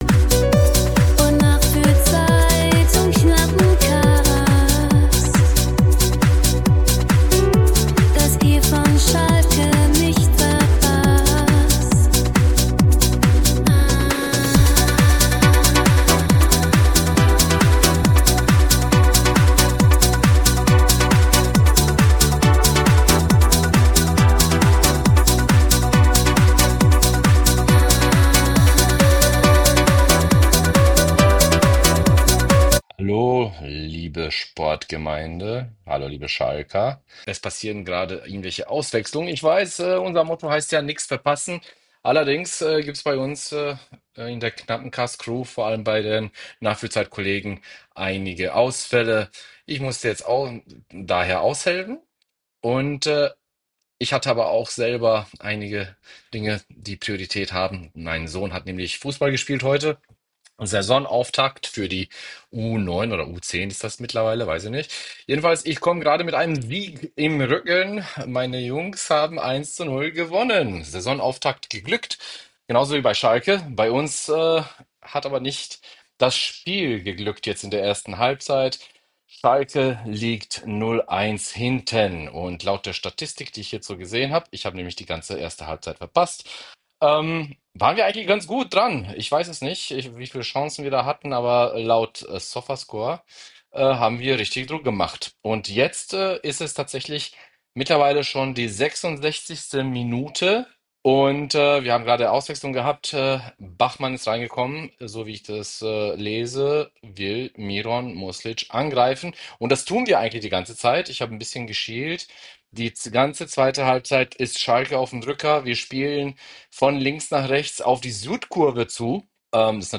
Schalke Audio Re-Live vom 13.09.2025